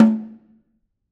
Snare2-HitNS_v6_rr1_Sum.wav